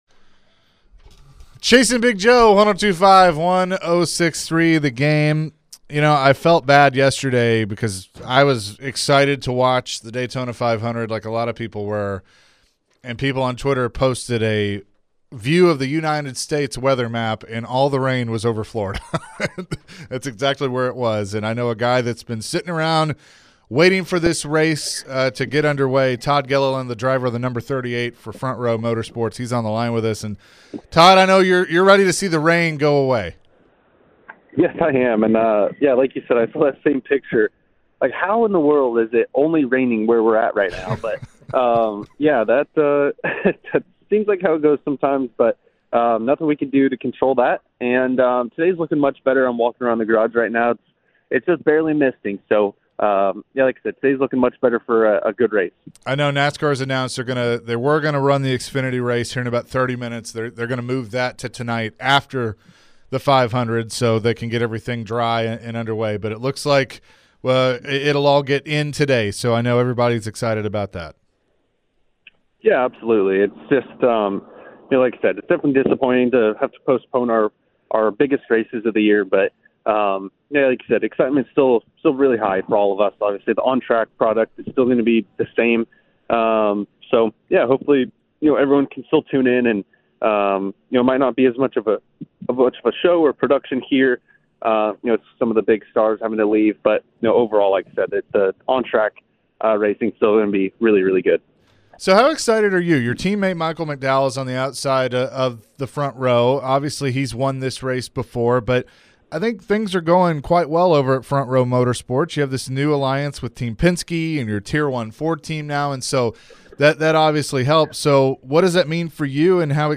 Todd Gilliland Interview (02-19-24)